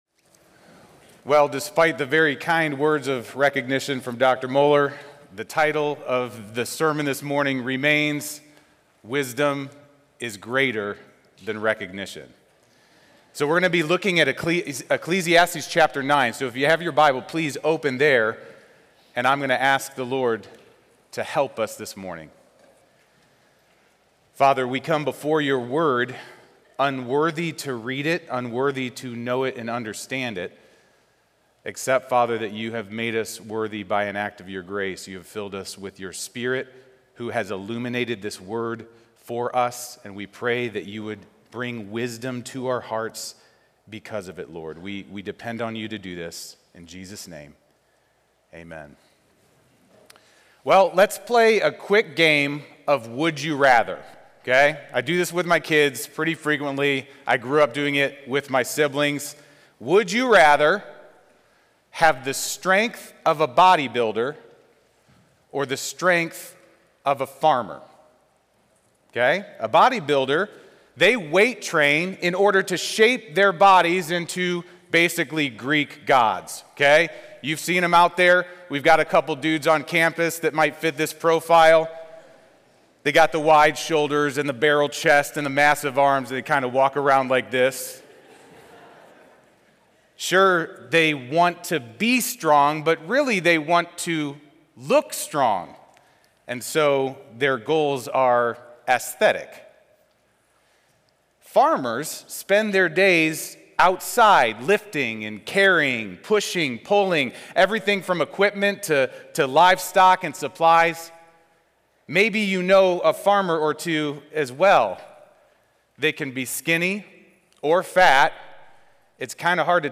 Home · Chapel · Wisdom is Greater Than Recognition